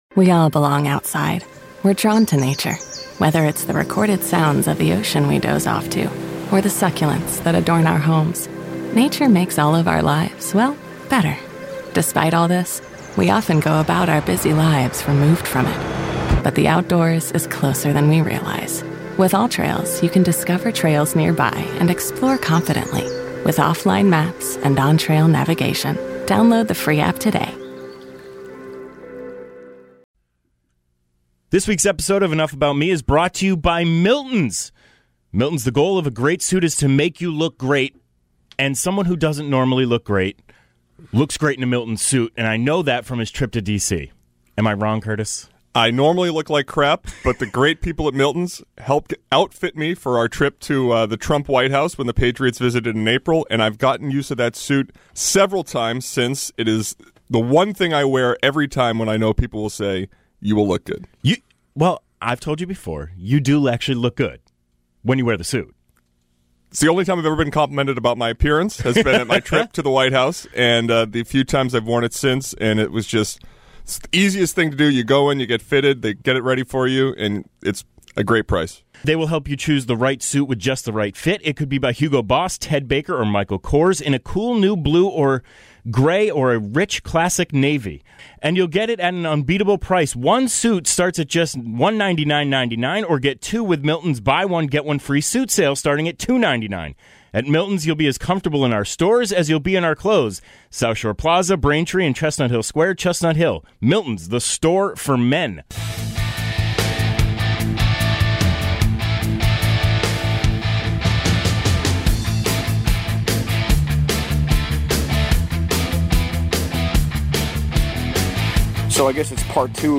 extended conversation